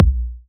edm-kick-54.wav